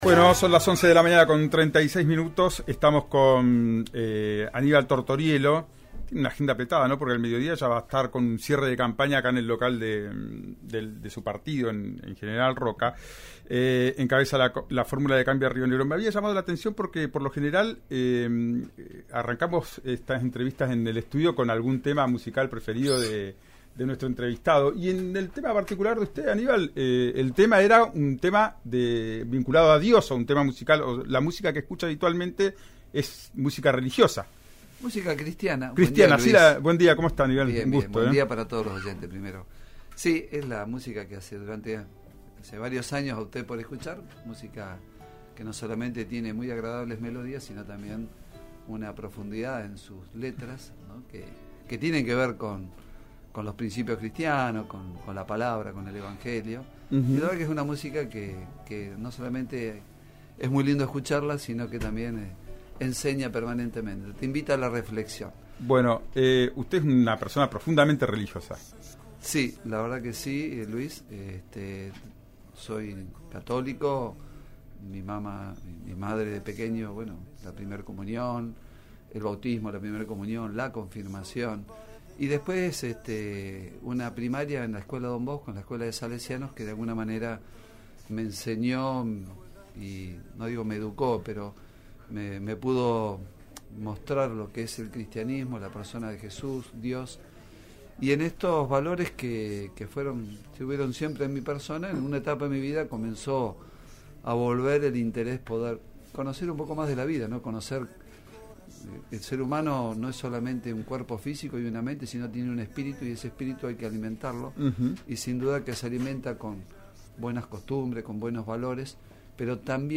El candidato de Cambia Río Negro dio declaraciones en los estudios de Río Negro Radio, a tres día de las elecciones provinciales.